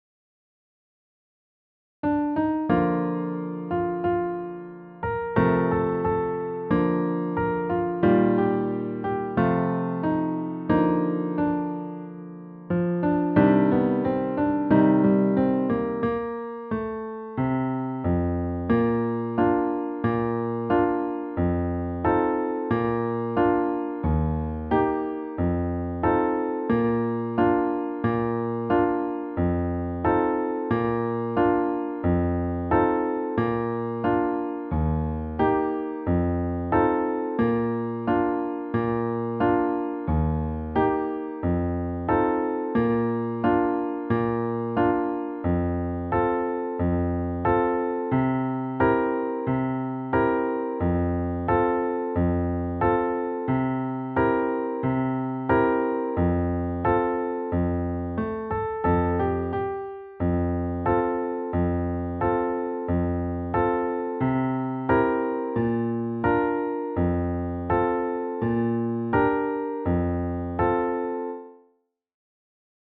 eurydiceinstrumental.mp3